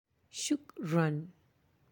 (shukran)